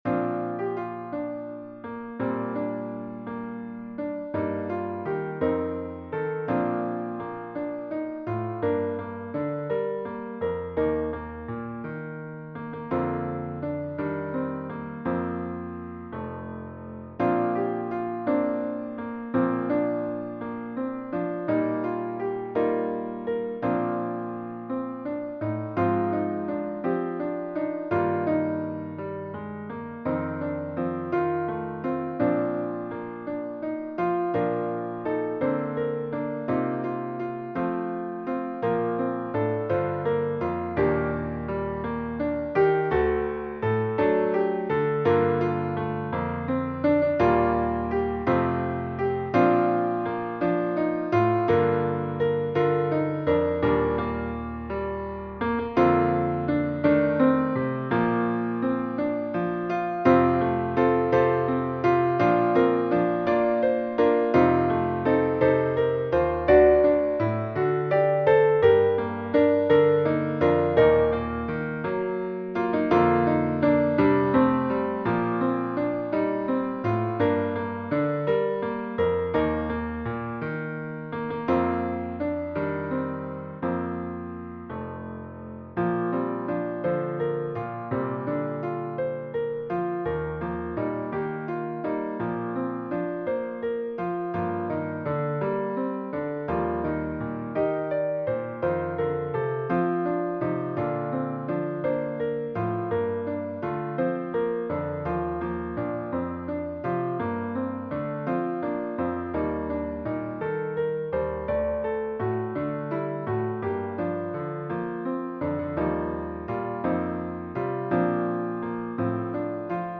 His Eye Is on the Sparrow Hymn #1005 written for SATB with Piano Accompaniment.
Voicing/Instrumentation: SATB We also have other 17 arrangements of " His Eye Is On The Sparrow ".